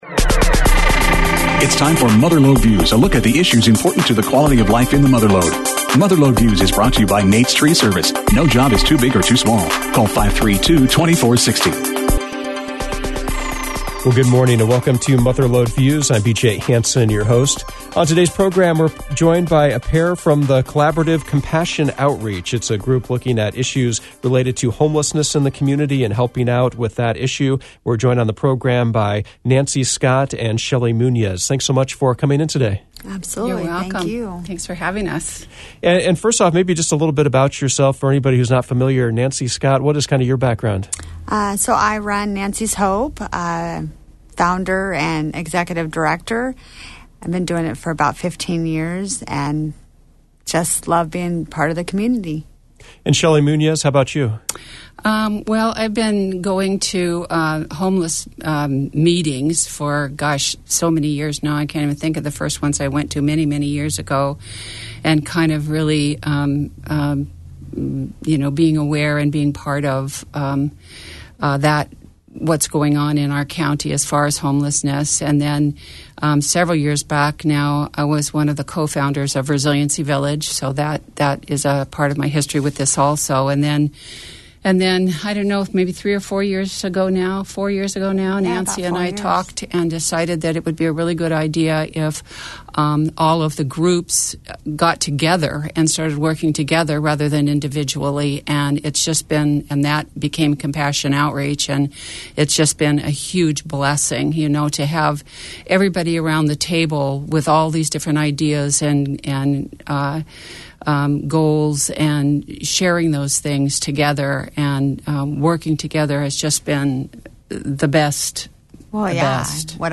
Mother Lode Views featured a conversation with leaders from Compassion Outreach, a collaborative working on initiatives related to homelessness.